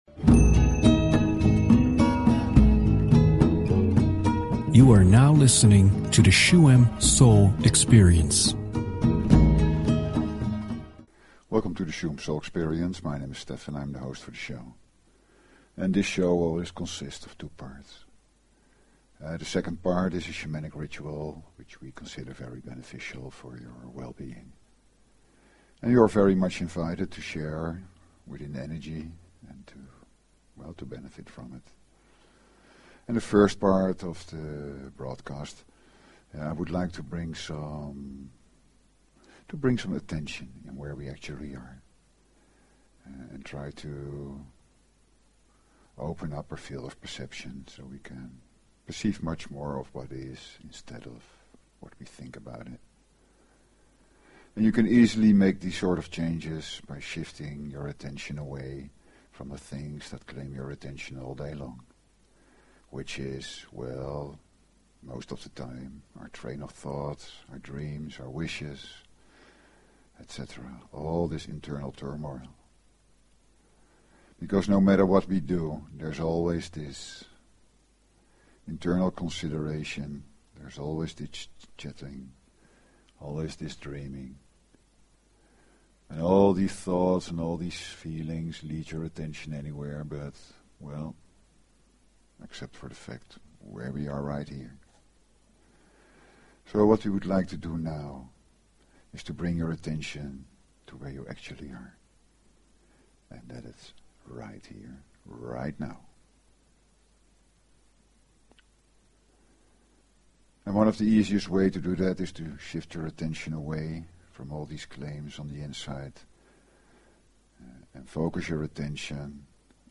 Talk Show Episode, Audio Podcast, Shuem_Soul_Experience and Courtesy of BBS Radio on , show guests , about , categorized as
Shuem Soul Experience is a radio show with:
During this ritual it is best to listen through headphones and sit or lie down and just let the sounds flow through.